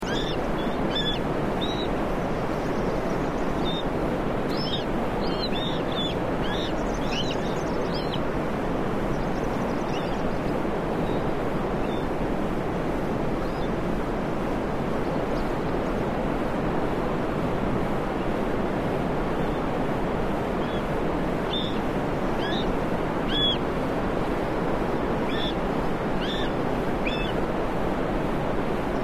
Aguilucho Cola Corta Aguilucho Puneño
Short-tailed Hawk Puna Hawk